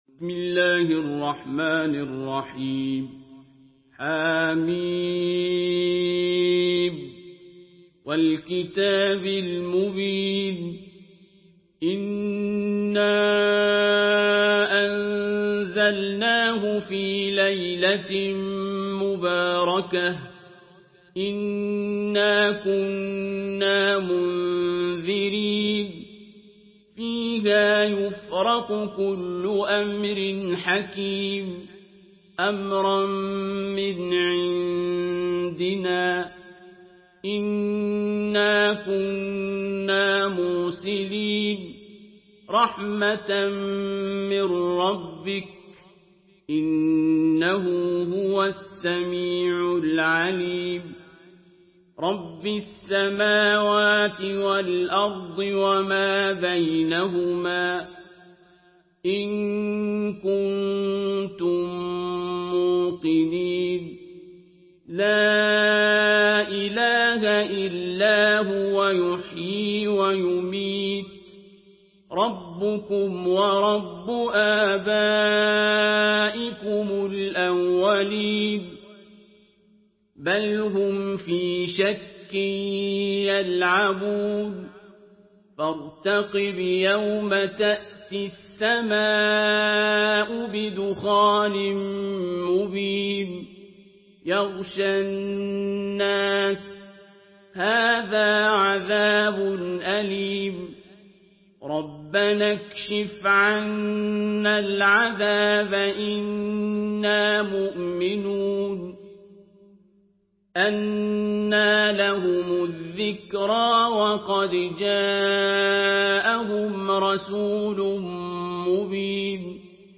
سورة الدخان MP3 بصوت عبد الباسط عبد الصمد برواية حفص
مرتل